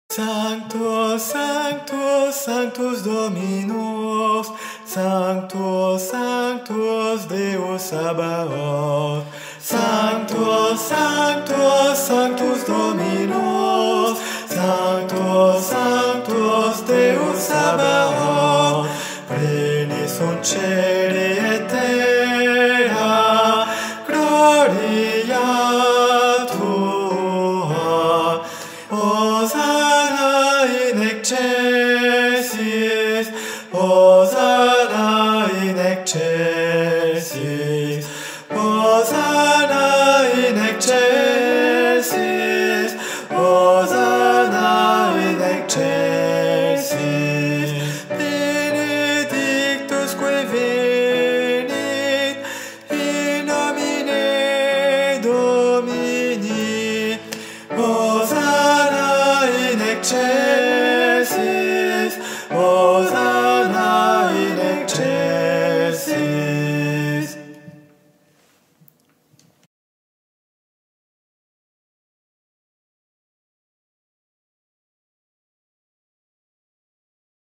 R�p�tition de la pi�ce musicale N� 519
Missa Pro Europa - Sanctus - Guide voix - Sopranos.mp3